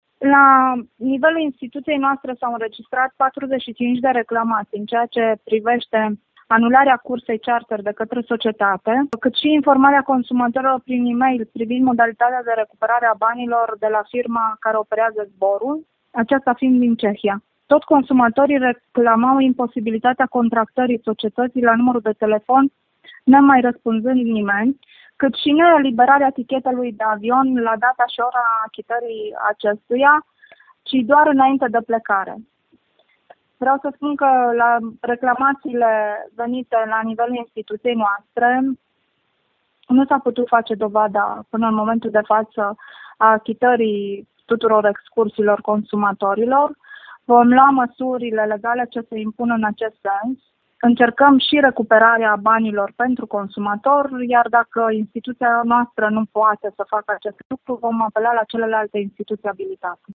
Șeful Comisariatului pentru Protecția Consumatorului Timiș, Mihaela Ignat, susține că se fac eforturi pentru ca oamenii să își primească înapoi banii pe care i-au plătit: